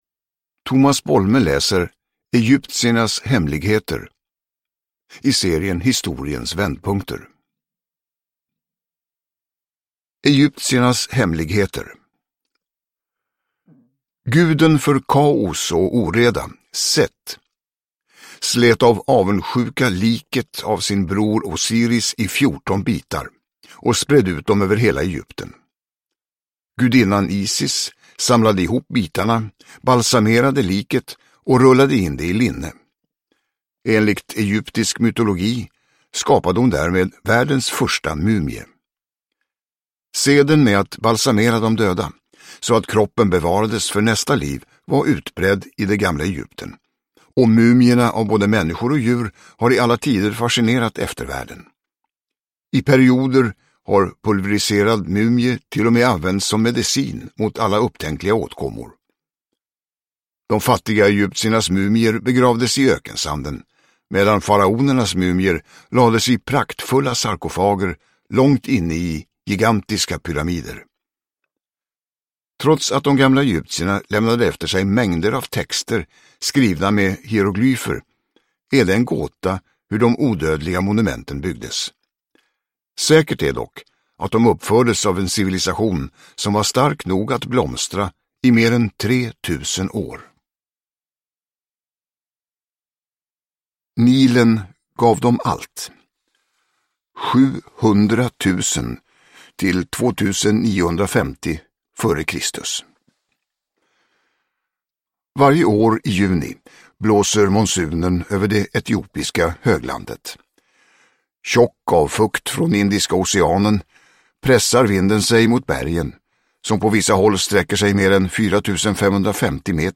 Egyptiernas hemligheter (ljudbok) av Else Christensen